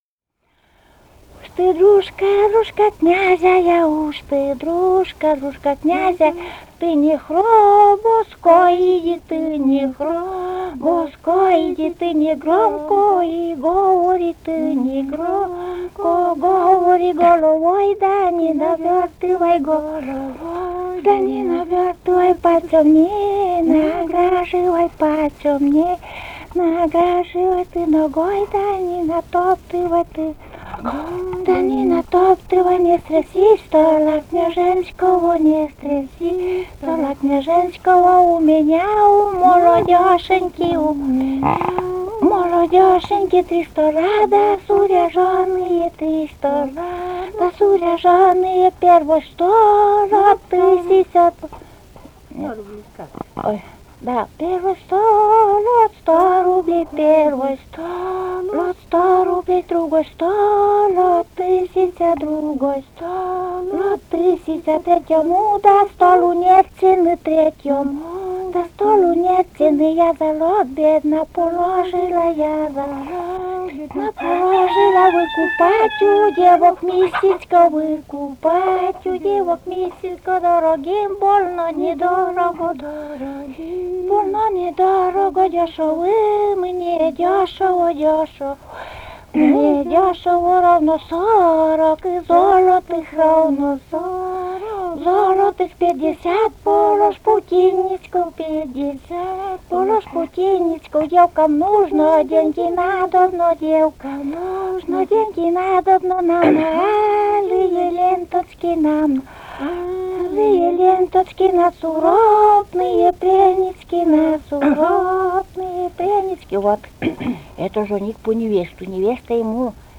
Живые голоса прошлого 125. «Уж ты, дружка» (свадебная).